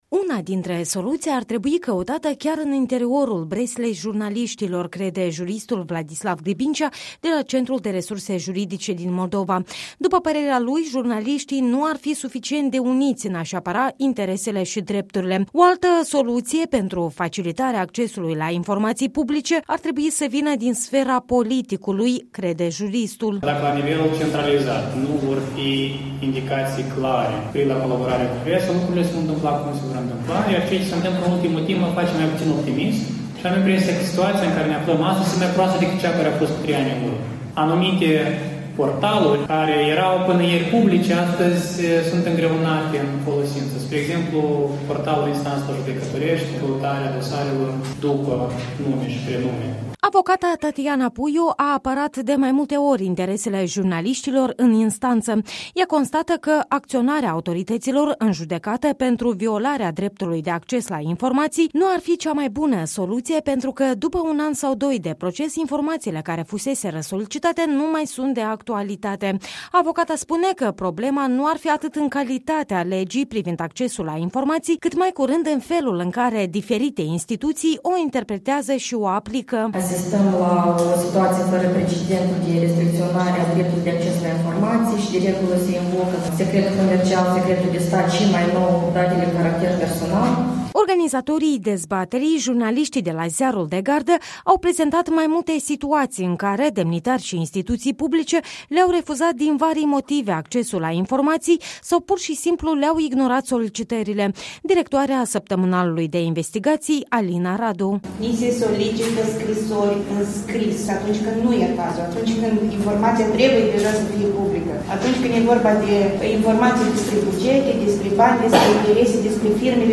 O dezbatere la Chișinău, în preajma Zilei Mondiale a Libertăţii Presei.